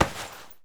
foley_combat_fight_grab_throw_08.wav